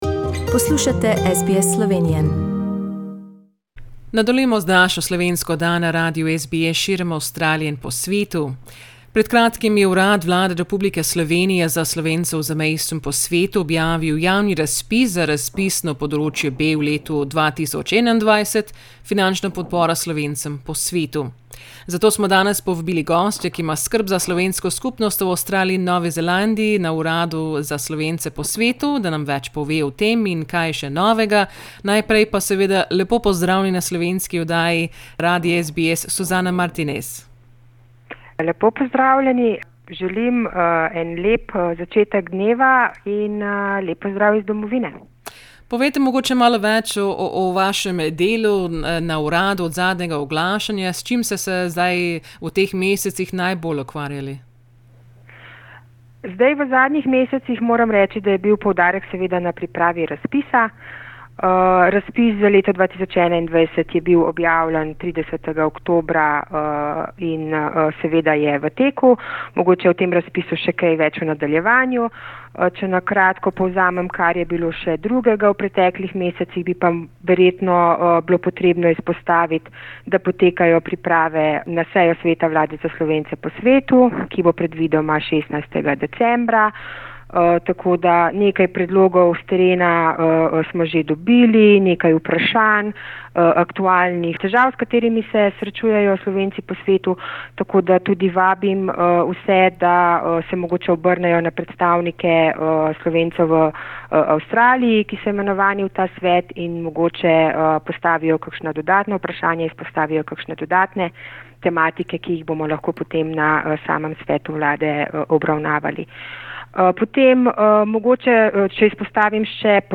smo se pogovarjali o podrobnostih razpisa. Rok prijave je do 30. novembra 2020.